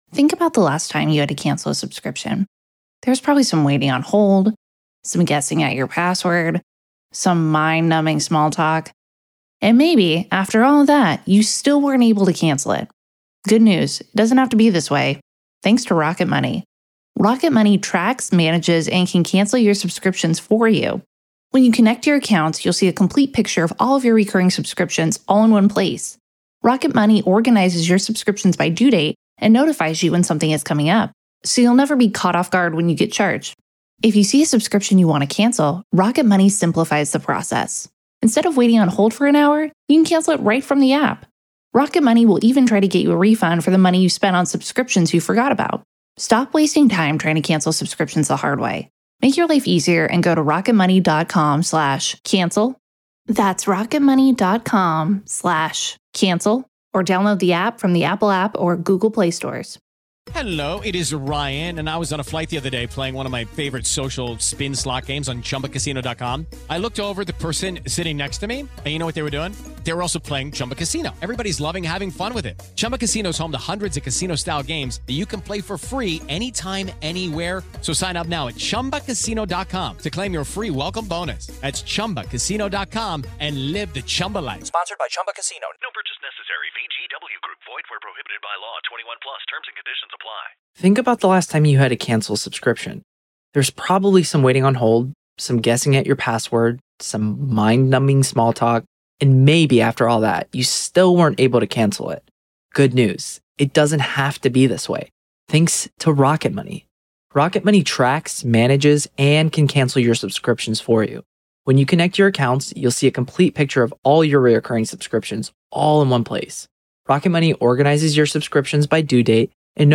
LIVE COURTROOM COVERAGE — NO COMMENTARY
There is no editorializing, no added narration, and no commentary — just the court, the attorneys, the witnesses, and the judge.